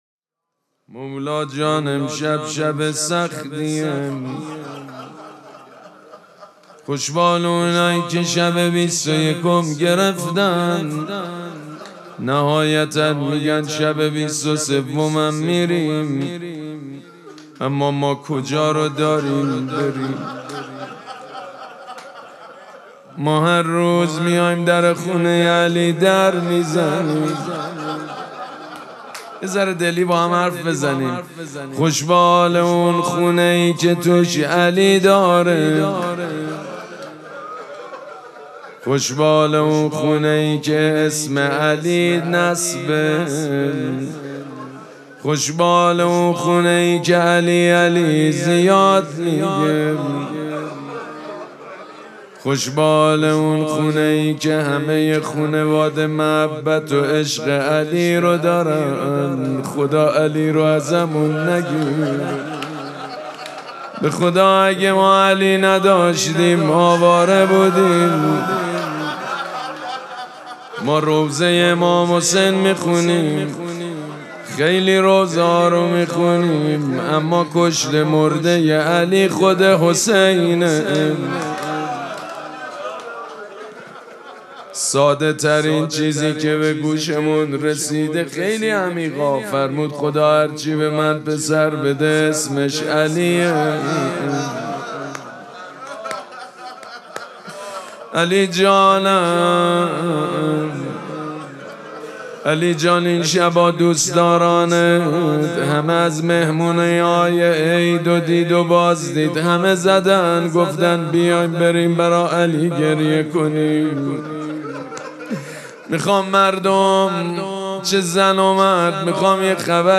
مراسم مناجات شب بیست و دوم ماه مبارک رمضان
حسینیه ریحانه الحسین سلام الله علیها
روضه